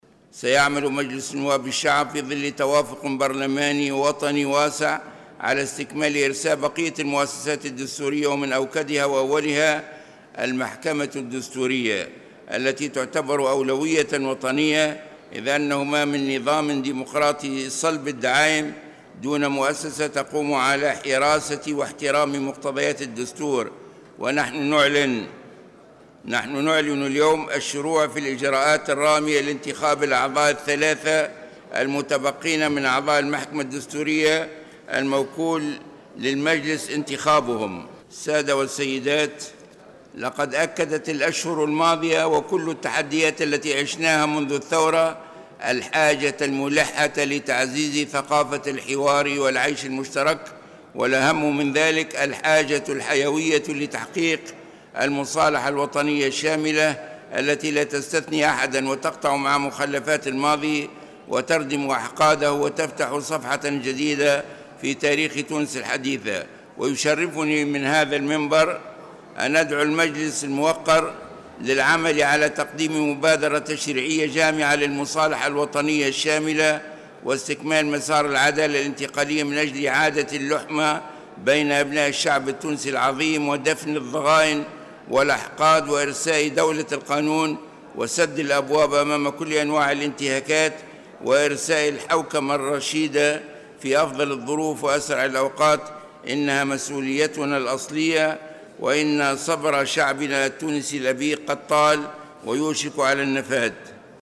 أعلن رئيس مجلس نواب الشعب راشد الغنوشي، في كلمته خلال الجلسة العامة المخصصة لمنح الثقة لحكومة إلياس الفخفاخ، اليوم الأربعاء، الشروع في الإجراءات الرامية لانتخاب الأعضاء الثلاثة المتبقين بالمحكمة الدستورية.